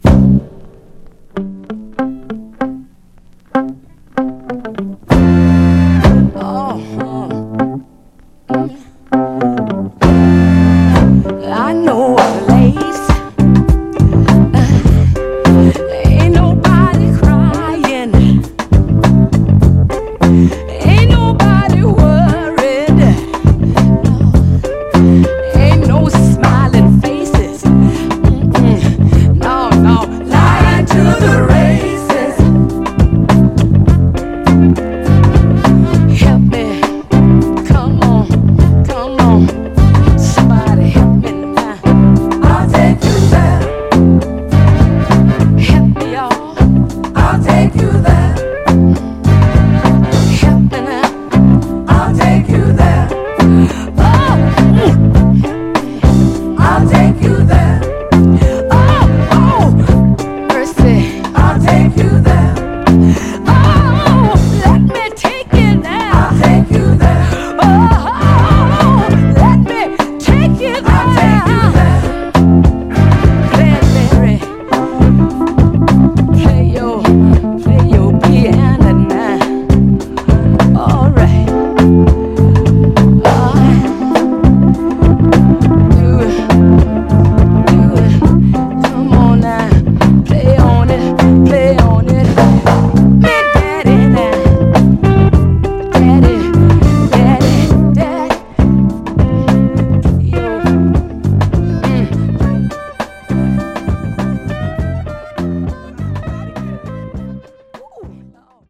父親同伴のゴスペル・ソウル・ファミリー
特大サザン・ソウル・クラシックです！
盤はいくつか細かいスレ箇所ありますが、グロスがありプレイ良好です。
※試聴音源は実際にお送りする商品から録音したものです※